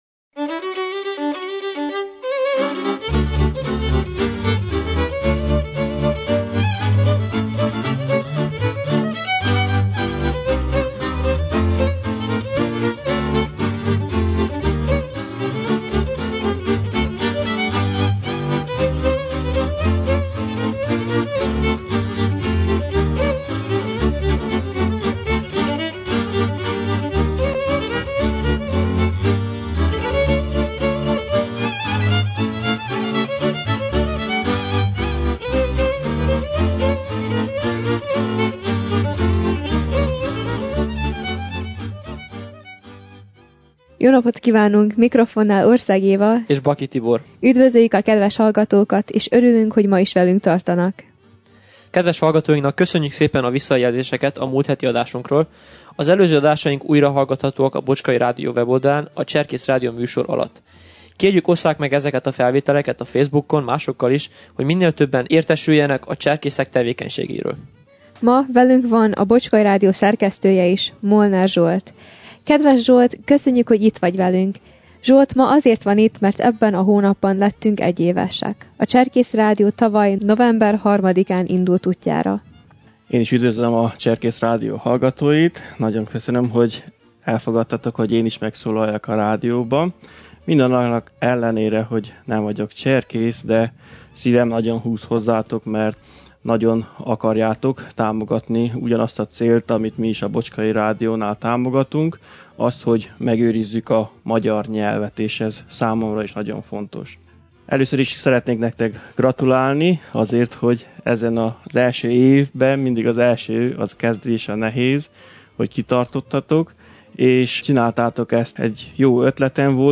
Cserkész hirdetések A népdal felvételek a 2005-ös fillmorei JUBI tábor alatt lett készítve.